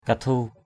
/ka-tʱu:/ 1.